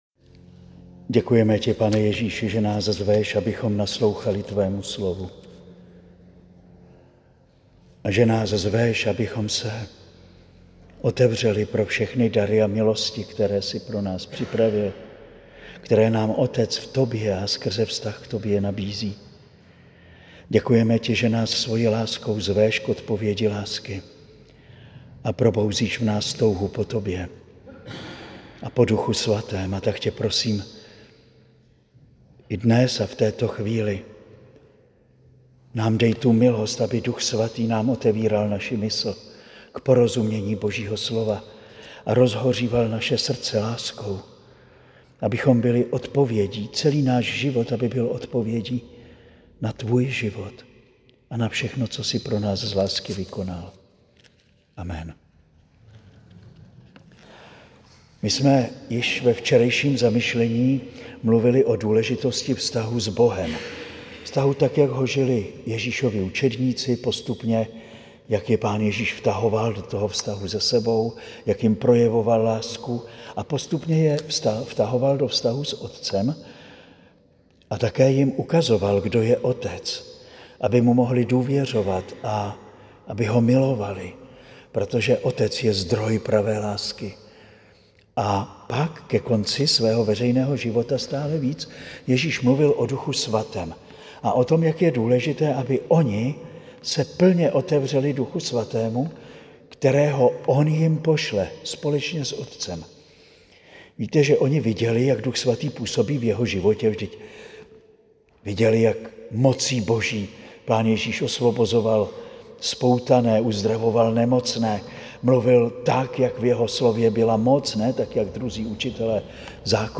Promluva zazněla na duchovní obnově v Klatovech v rámci přípravy na Slavnost Seslání Ducha Svatého.